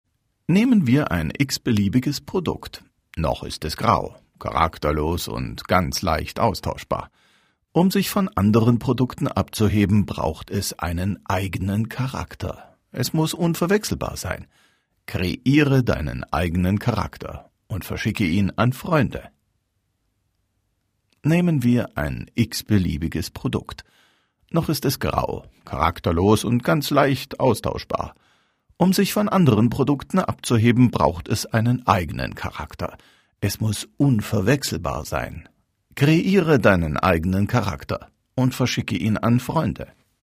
Deutscher Sprecher, Werbesprecher für Einkaufsparks und Center, Telfonjingles, Profischauspieler, Hörbücher, Hörspiele, Produzent, all inklusive, Overvoice, Voiceover, Dokfilm, Reportagen, Heimatfilm, Kommentar, Kurzfilm, Offsprecher, Synchron, Spielfilm, Dokumentation,
Sprechprobe: Werbung (Muttersprache):